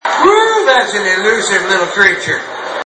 Category: Comedians   Right: Personal
Tags: Ron White comedy tater salad can't fix stupid